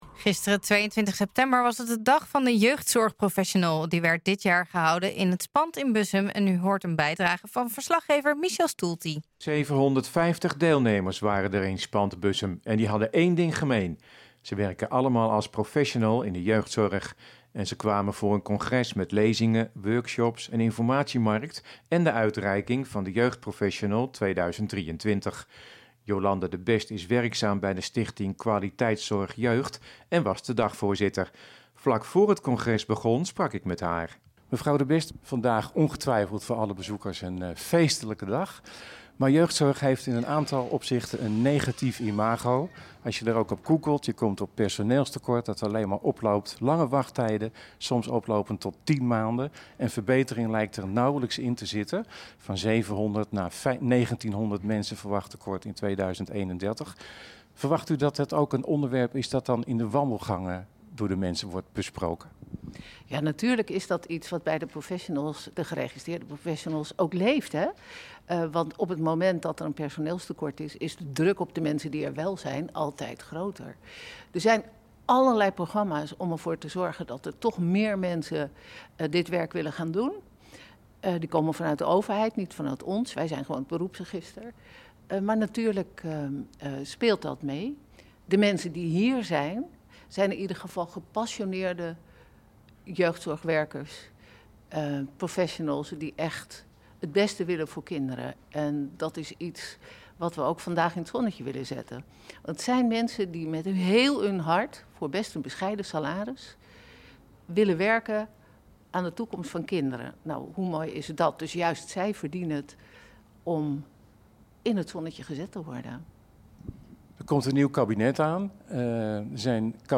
Gisteren, 22 september, was het de Dag van de Jeugdzorgprofessional. Die werd dit jaar gehouden in Spant in Bussum. U hoort een bijdrage van verslaggever